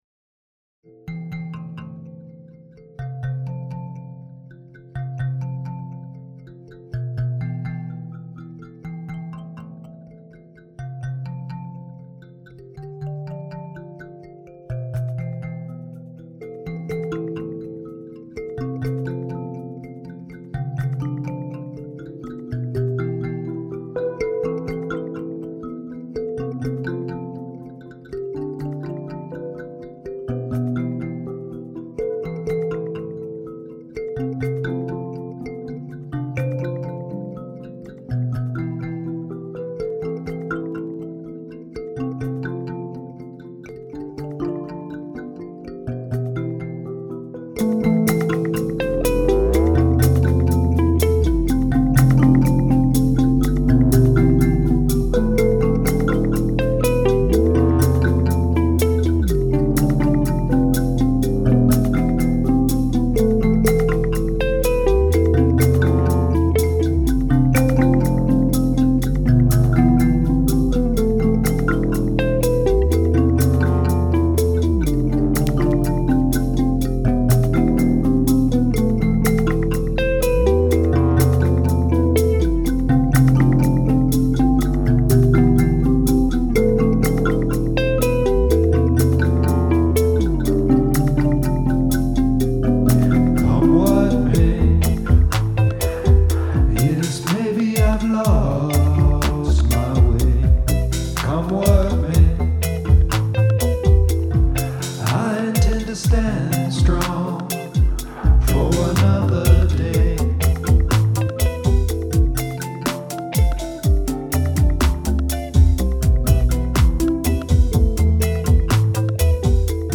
An Mbira and Reggae groove sprinkled with Garage Bass